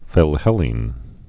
(fĭl-hĕlēn) also phil·hel·len·ist (-hĕlə-nĭst)